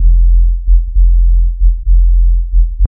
Clean Kriss Vector shot, short, realistic, no environment, deep and bass-heavy tone, smooth, loop-ready, seamless, mono 48kHz 0:03 Dub step, skrillex, anime, 0:41 human battle cry, sounds angry with a bass heavy roar 0:04
clean-kriss-vector-shot-s-6vc4amge.wav